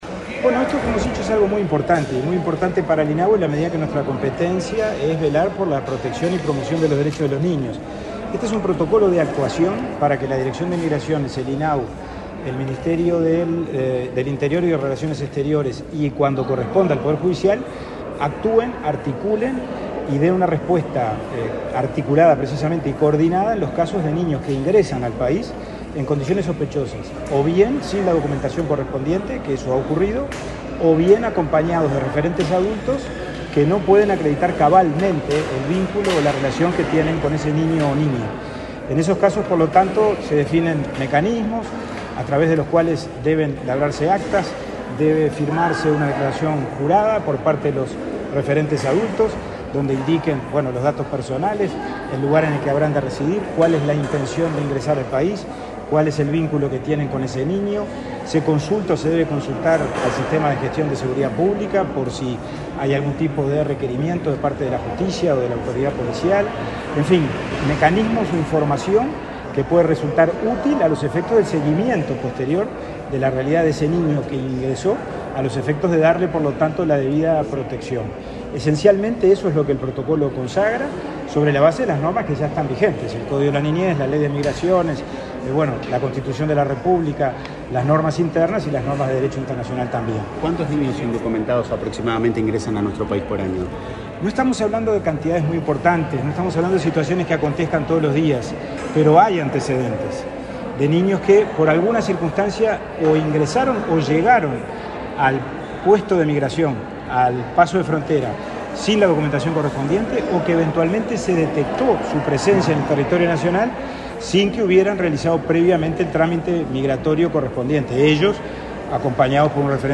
Declaraciones a la prensa del presidente de INAU, Pablo Abdala
Tras participar en la firma de convenio entre el Instituto del Niño y Adolescente del Uruguay (INAU) y los ministerios de Relaciones Exteriores e Interior, para aplicar el protocolo que define criterios de acción respecto a menores indocumentados en la frontera, este 6 de diciembre, el presidente de INAU realizó declaraciones a la prensa.
abdala prensa.mp3